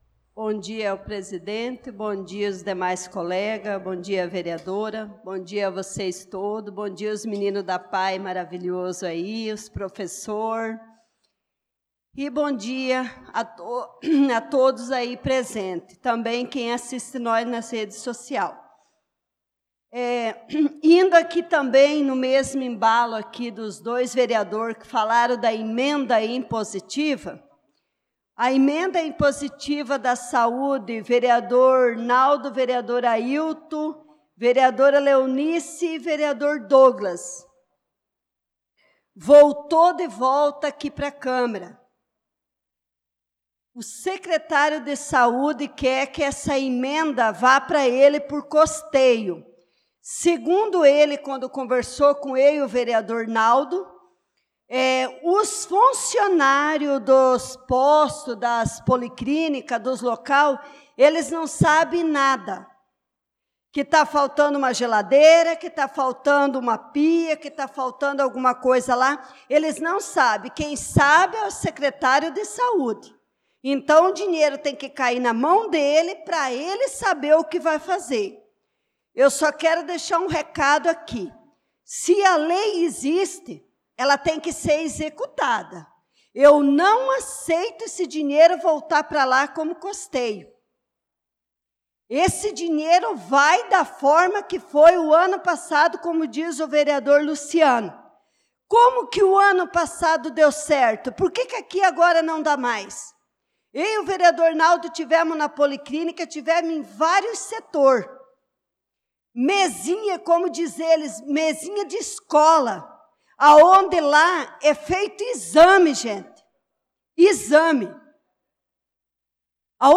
Pronunciamento da vereadora Leonice Klaus na Sessão Ordinária do dia 05/05/2025